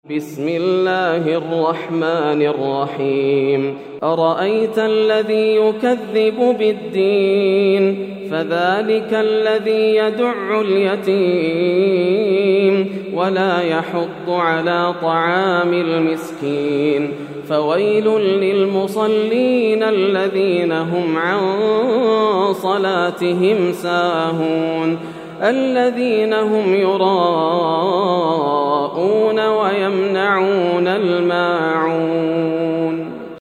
سورة الماعون > السور المكتملة > رمضان 1431هـ > التراويح - تلاوات ياسر الدوسري